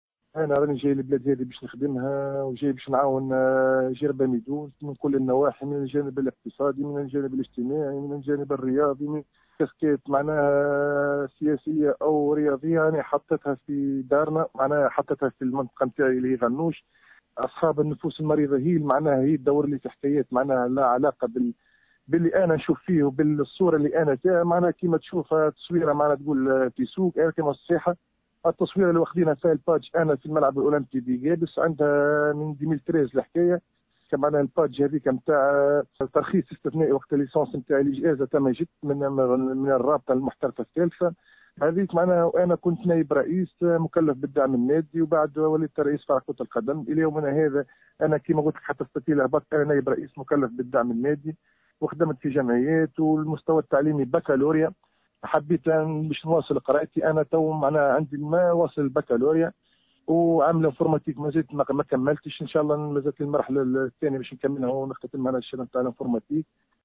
وأضاف في تصريح لمراسلة الجوهرة اف ام، أن أصحاب النفوس المريضة تحاول تشويهه، مشيرا إلى أن مستواه التعليمي باكالوريا، وأنه بصدد التكوين في مجال الإعلامية.